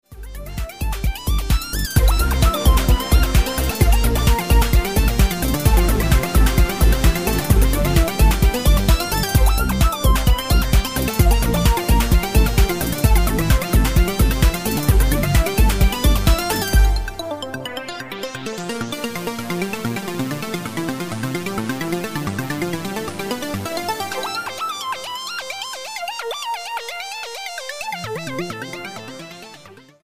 Keyboards, Computer
Piano Ballad, Elektro, Kinderlied,
Techno, Rock ...